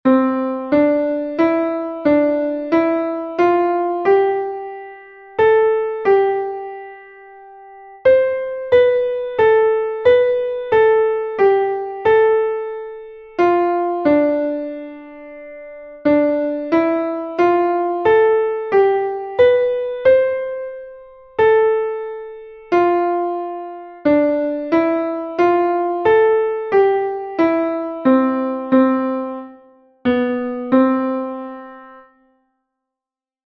Melodic and rhythmic dictation | MUSIC FACTORY 2 - UNIT 6